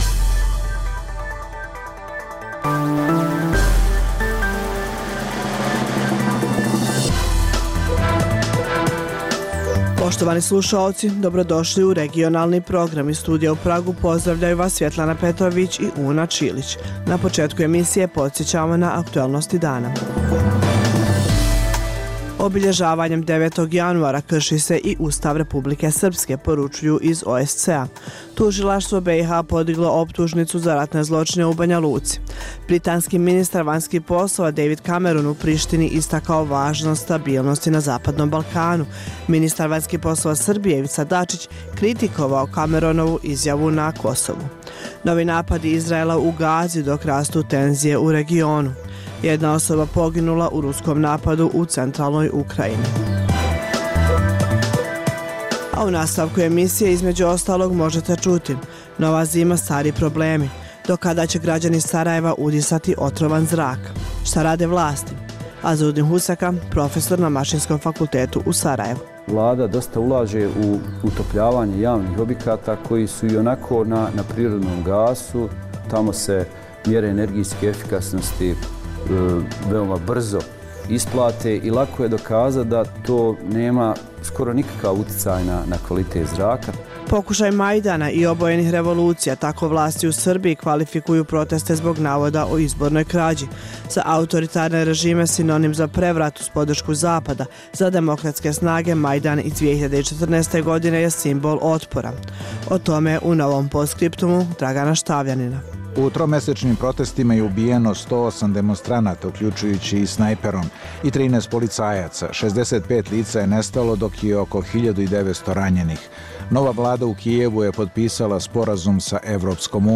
Emisija o dešavanjima u regionu (BiH, Srbija, Kosovo, Crna Gora, Hrvatska) i svijetu. Prvih pola sata emisije sadrži najaktuelnije i najzanimljivije priče o dešavanjima u zemljama regiona i u svijetu (politika, ekonomija i slično). Preostalih pola sata emisije sadrži analitičke priloge iz svih zemalja regiona i iz svih oblasti, od politike i ekonomije, do kulture i sporta.